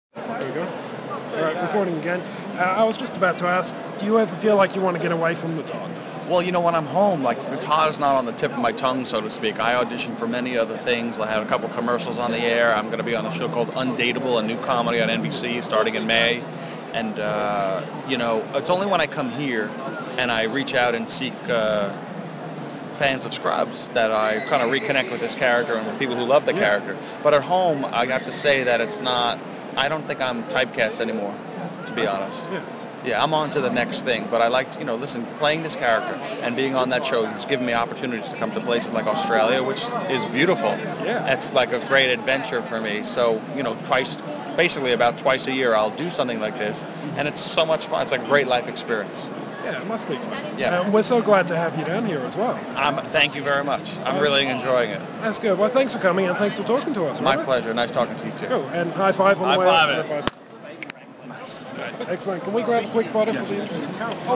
The audio is in two parts (required for high-five sound effects) and the transcript follows.
Category : Interviews